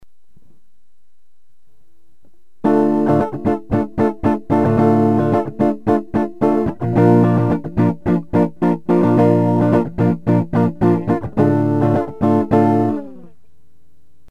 Купил сегодня дишманскую звуковуху C-Media 8738 так как из старой сын выломал линейный входтак вот. втыкаю гитару звучит лучше ...